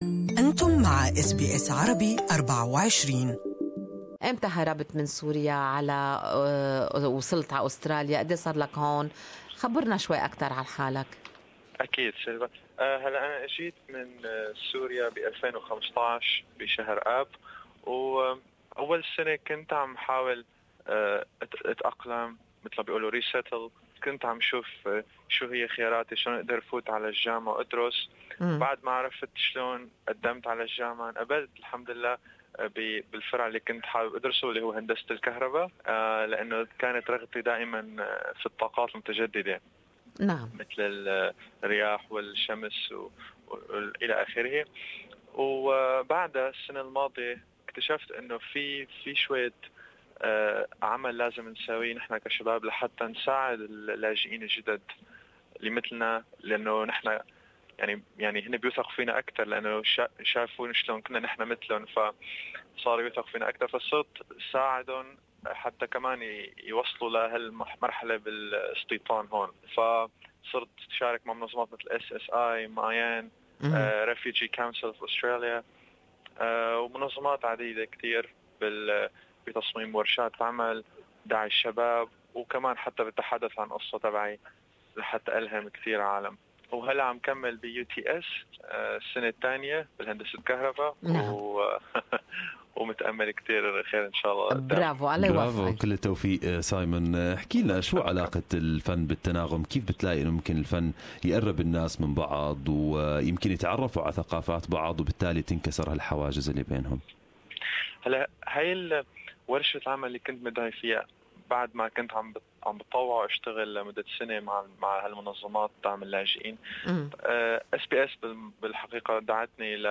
Harmony Art Collective is an initiave which sponsors underprivilged artists under supervision of renowned Australinan artists since 2015. Good Morning Australia interviewed